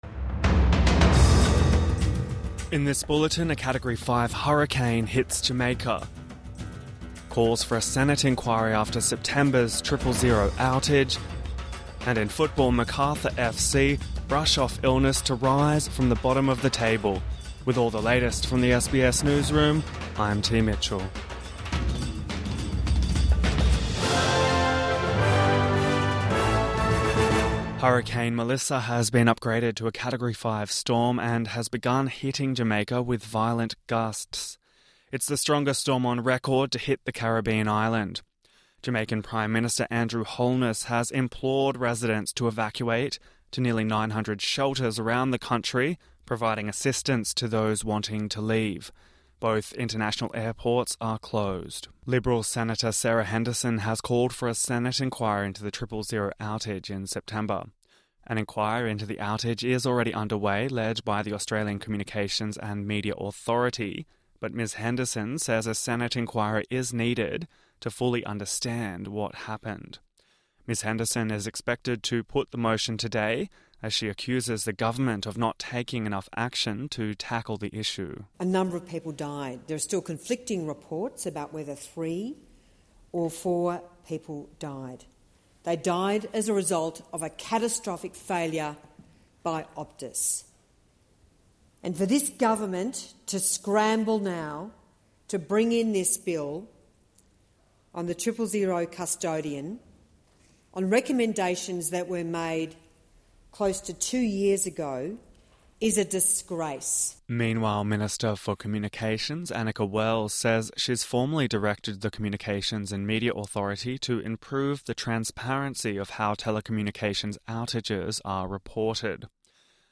Morning News Bulletin 28 October 2025